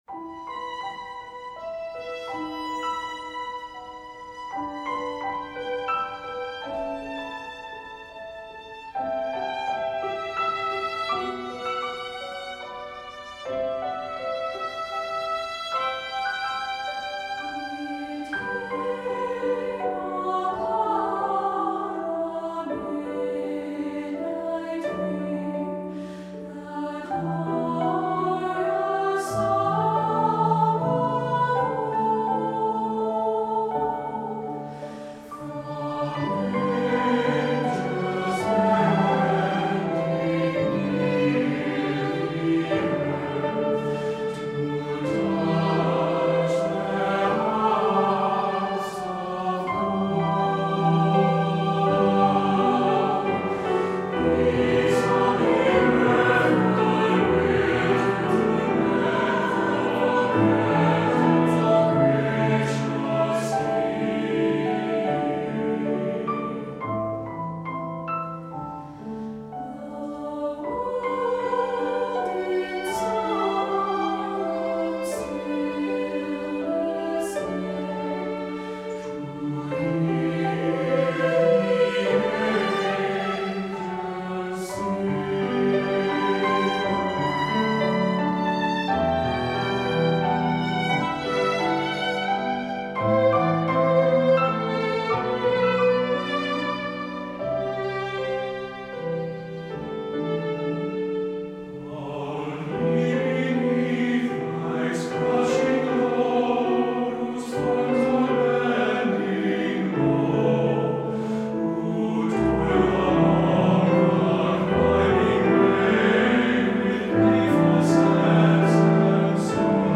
Voicing: SATB, Violin and Piano